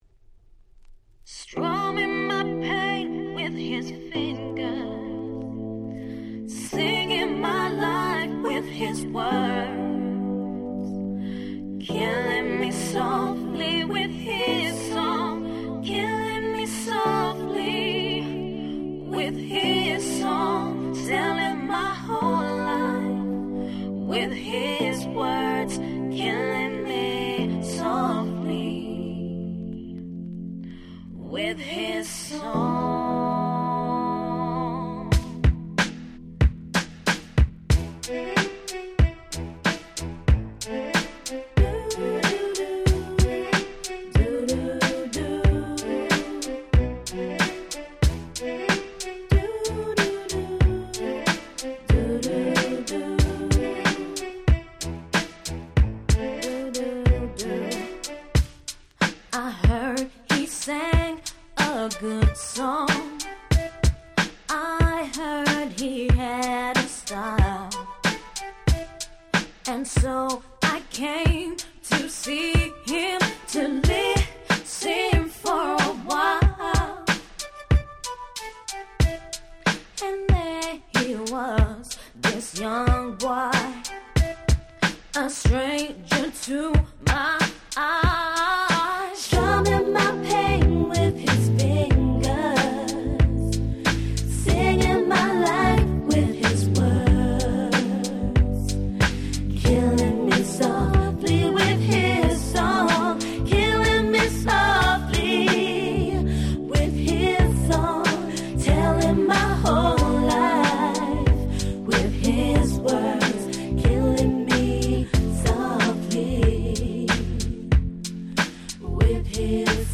Nice Cover R&B !!